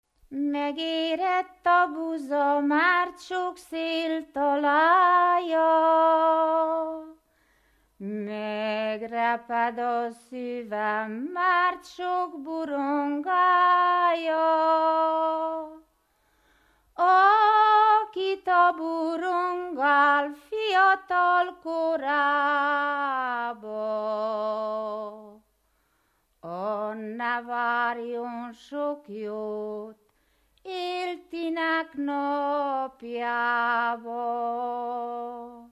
Moldva és Bukovina - Bukovina - Hadikfalva
Stílus: 3. Pszalmodizáló stílusú dallamok